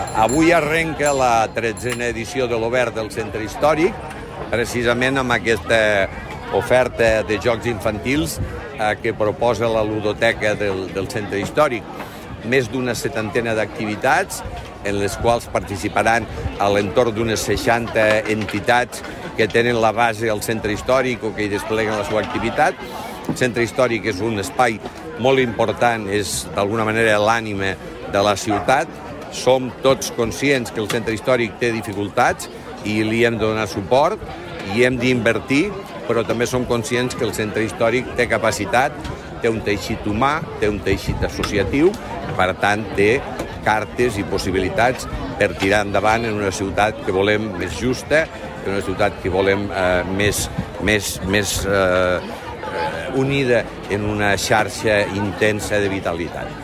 L’alcalde Miquel Pueyo agraeix la vitalitat cultural, cívica i associativa d’entitats i veïns en la inauguració de l’Obert Centre Històric
tall-de-veu-m-pueyo